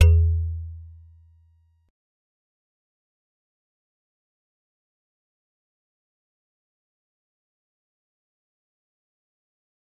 G_Musicbox-E2-pp.wav